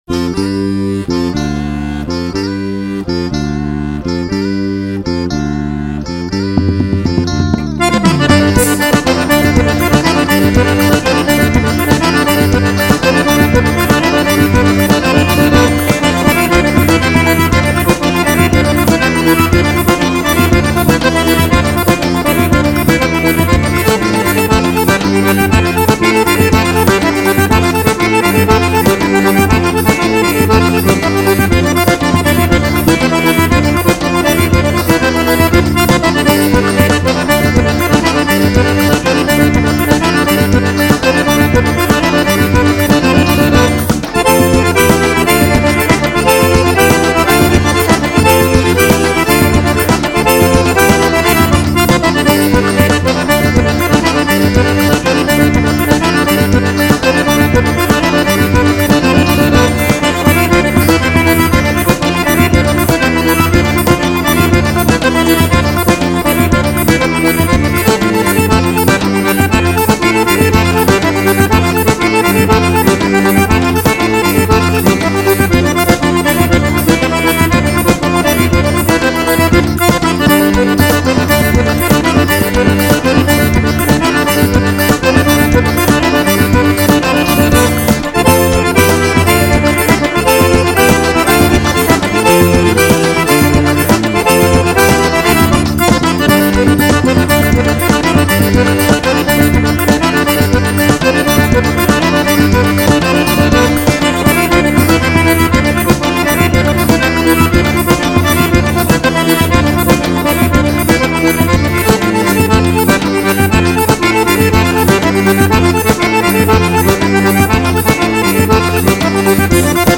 cantor, compositor e radialista.